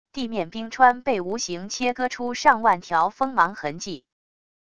地面冰川被无形切割出上万条锋芒痕迹wav音频